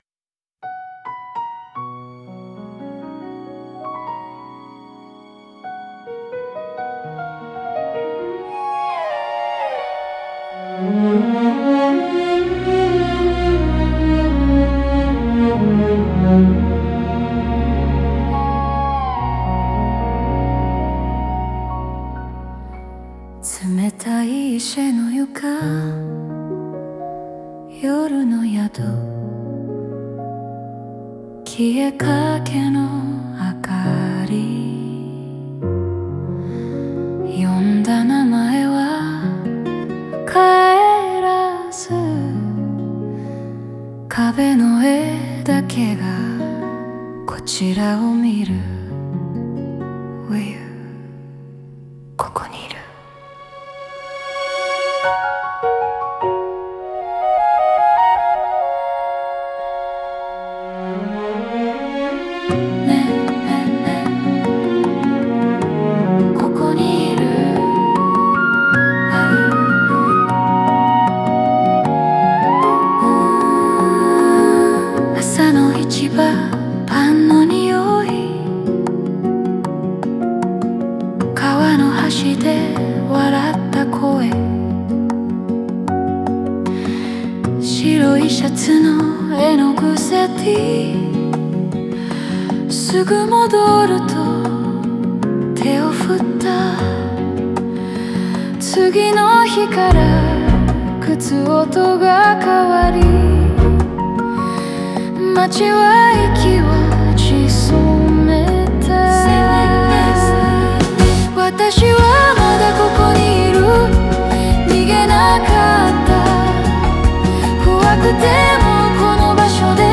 楽曲構成は内省から始まり、観客との呼応によって個人の物語が集団の体験へと変化していきます。